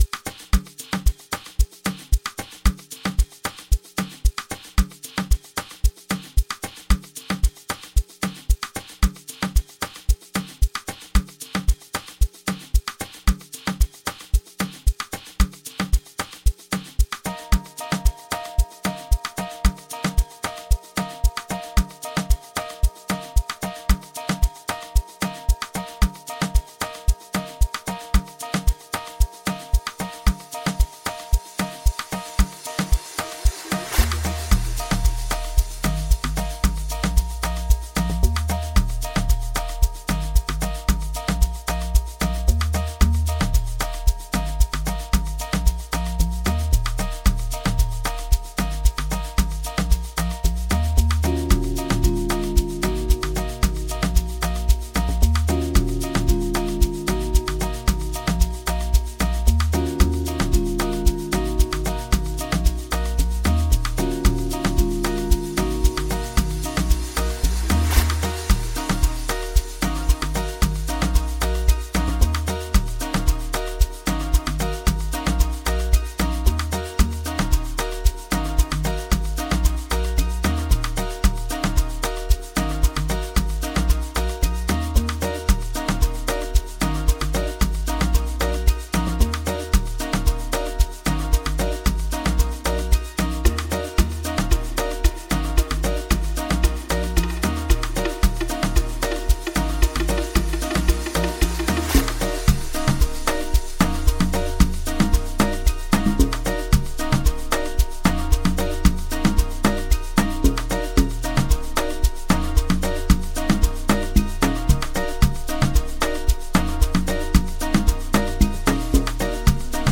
• Genre: Electronic